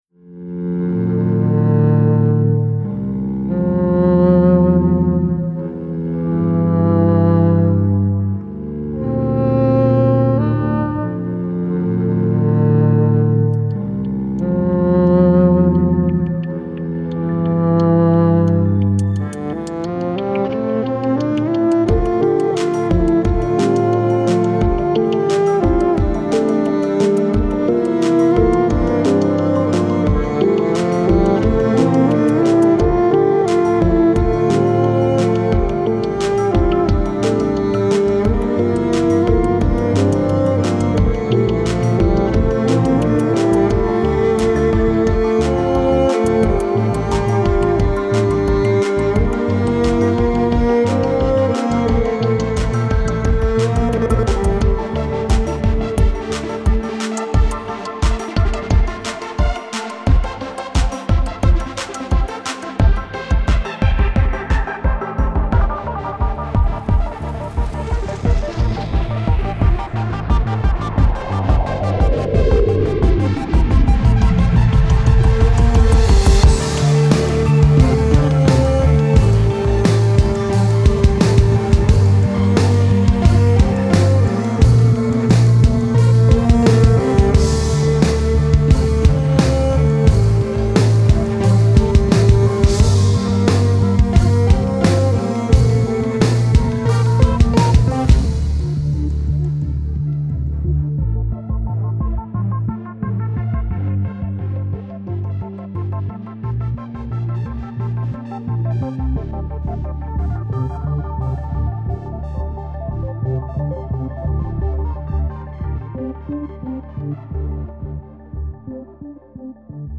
Midi Upright Bass